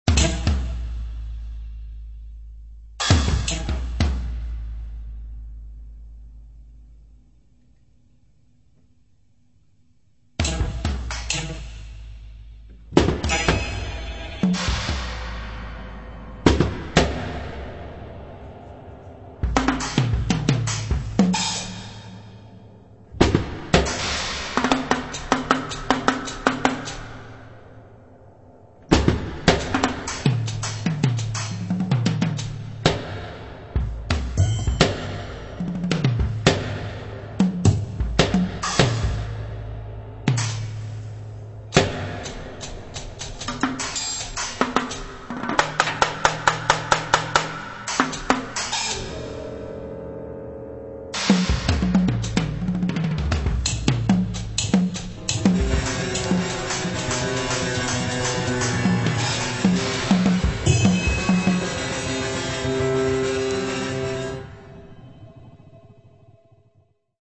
piano and prepared piano
percussion and electronics